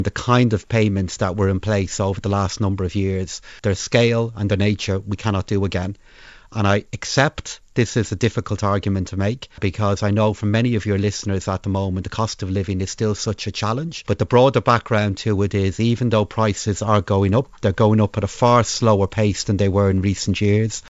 Finance Minister Paschal Donohoe, says the Government is not in a position to award once-off payments…………..